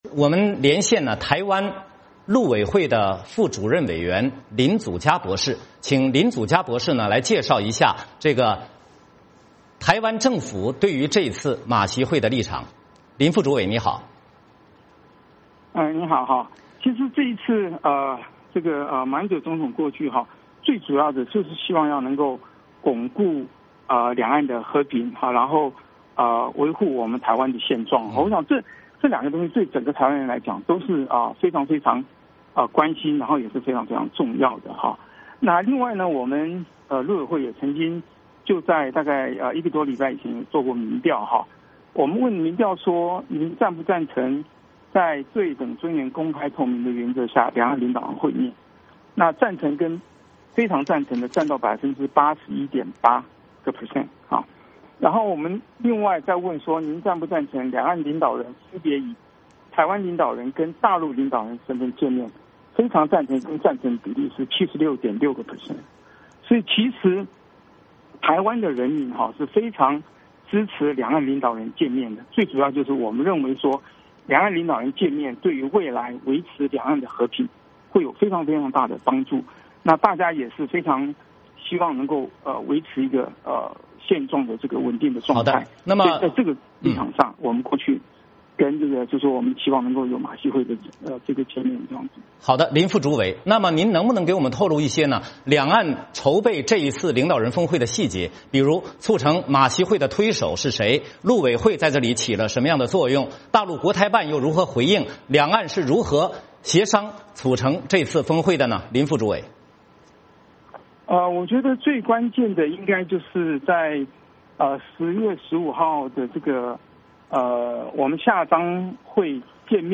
为什么马英九总统会有这样的表示？我们请台湾陆委会副主委林祖嘉博士为您分析。